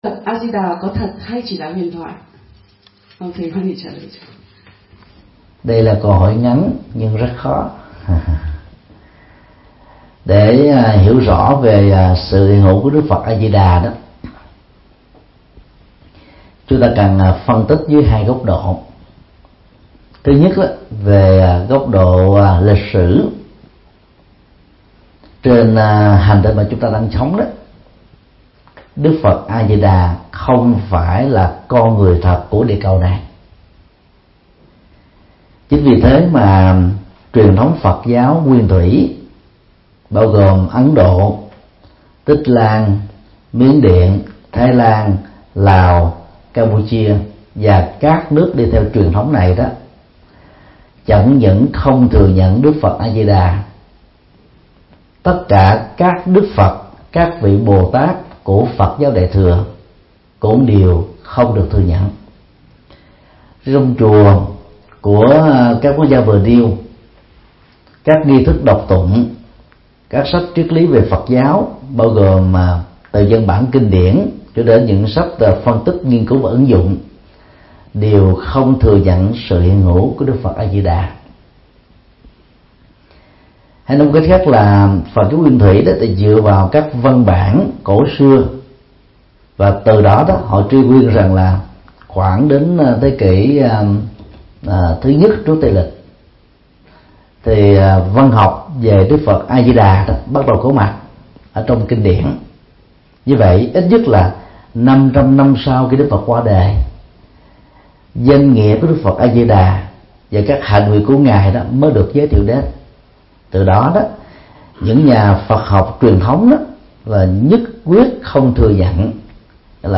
Vấn đáp: Phật A Di Đà có thật hay không?